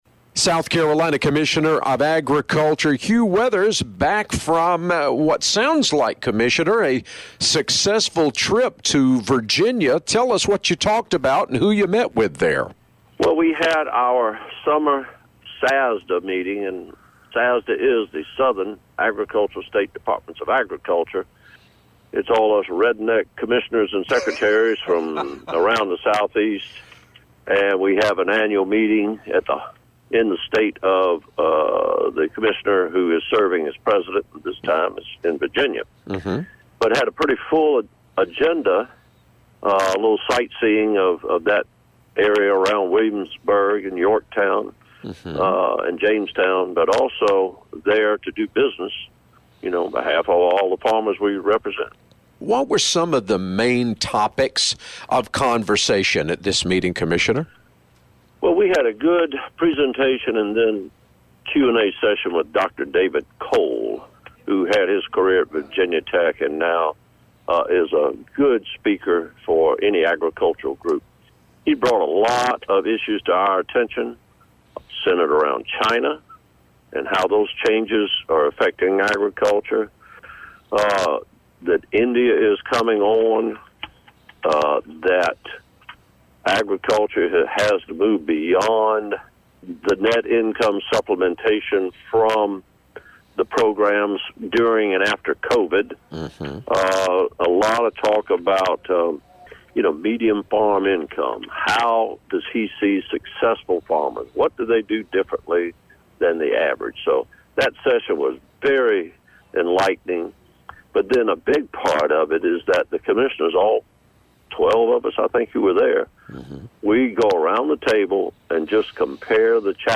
Every week Commissioner Hugh Weathers talks about what’s going on with agriculture in our state with The Southern Farm Network.
Check back each week to hear the latest news, or you can listen to past interviews here.